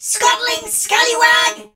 darryl_hurt_vo_01.ogg